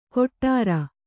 ♪ koṭṭāra